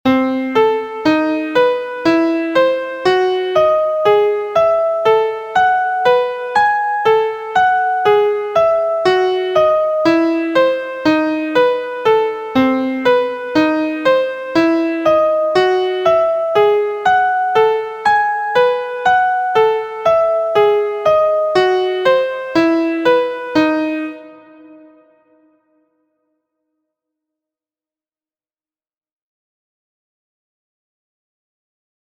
• This example shows both permutations of a 2 note modal sequence in C Lydian#2#5 scale using 6ths and ascending and descending one octave.